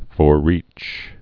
(fôr-rēch)